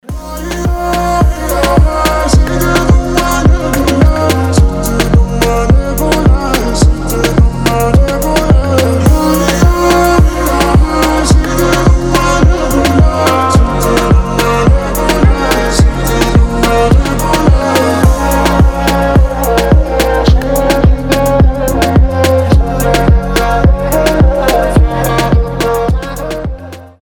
• Качество: 320, Stereo
мужской голос
ритмичные